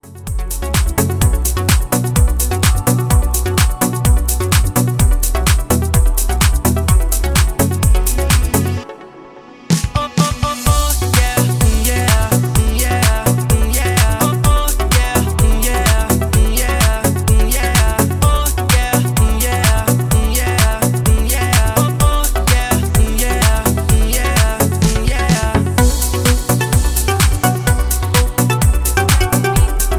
Backing track files: 2010s (1044)